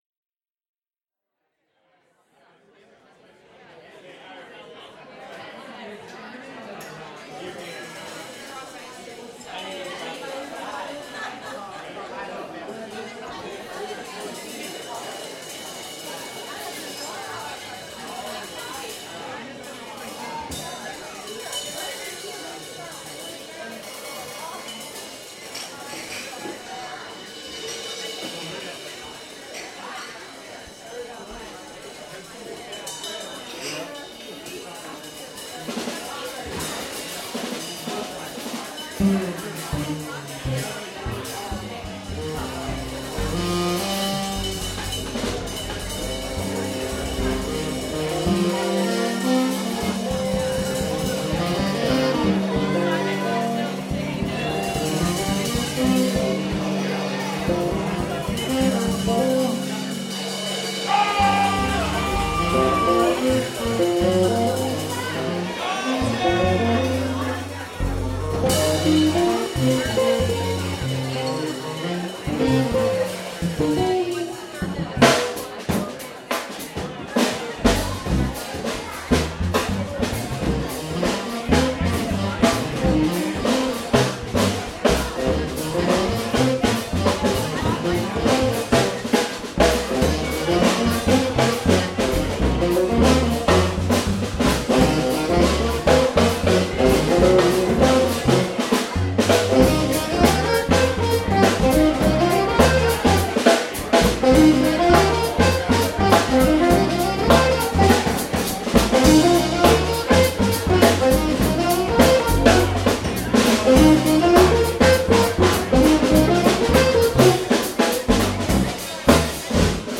Drums
Tenor
Guitar
Bass